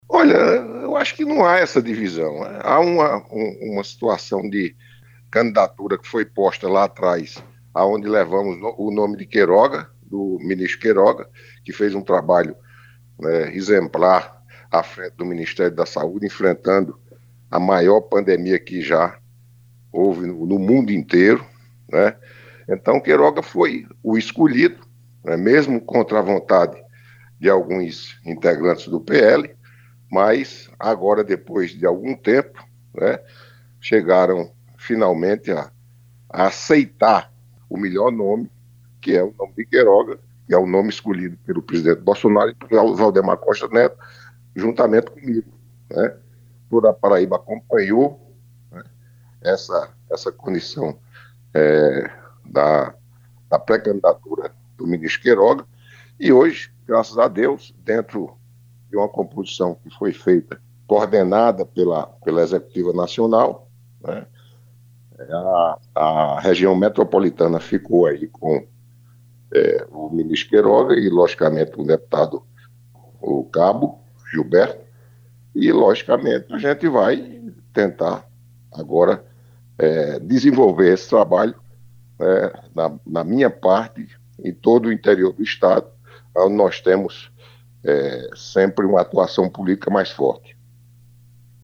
Os comentários de Roberto foram registrados pelo programa Correio Debate, da 98 FM, de João Pessoa, nesta terça-feira (09/04).